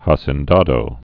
(häsĕn-dädō, äsĕn-) also ha·ci·en·da·do (häsē-ĕn-dädō, äsē-)